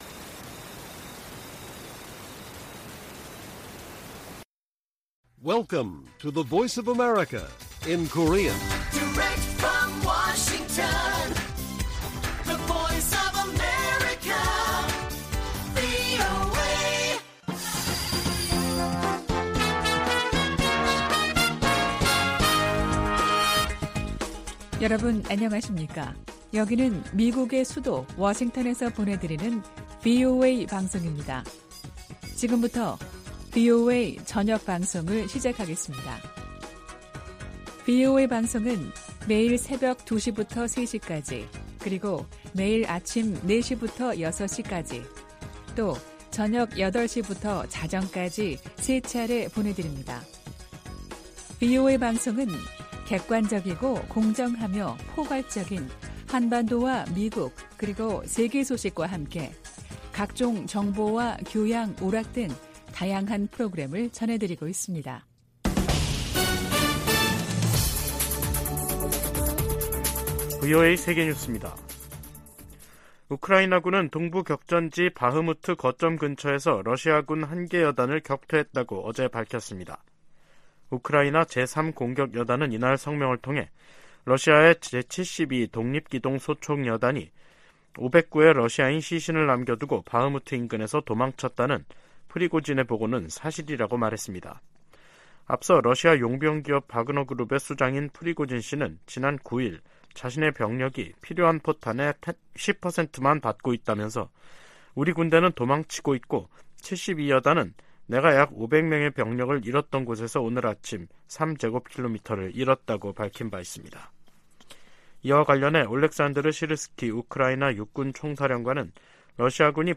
VOA 한국어 간판 뉴스 프로그램 '뉴스 투데이', 2023년 5월 11일 1부 방송입니다. 북한이 사이버 활동으로 미사일 자금 절반을 충당하고 있다고 백악관 고위 관리가 말했습니다. 미한 동맹이 안보 위주에서 국제 도전 과제에 함께 대응하는 관계로 발전했다고 미 국무부가 평가했습니다. 미 국방부가 미한일 3국의 북한 미사일 정보 실시간 공유를 위해 두 나라와 협력하고 있다고 확인했습니다.